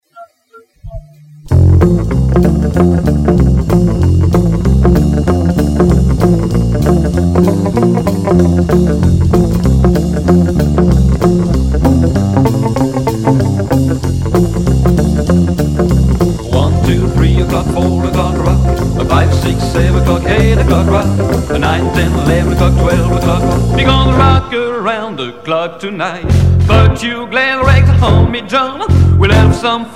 Rockabilly